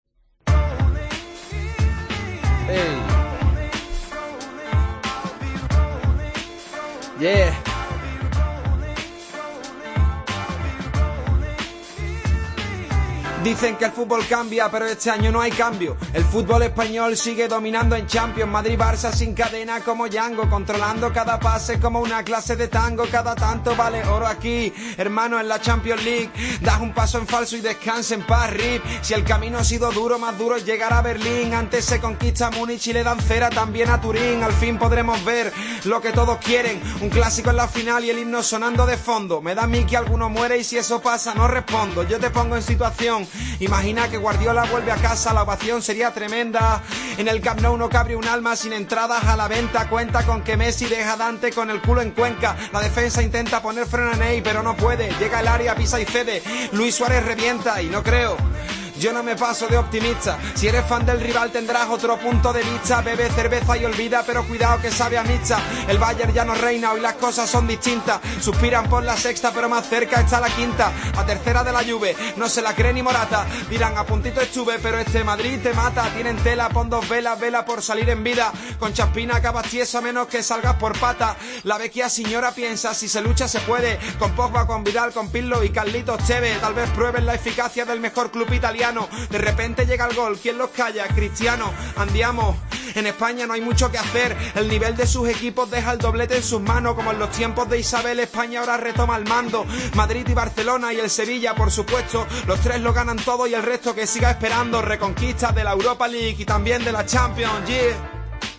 a ritmo de rap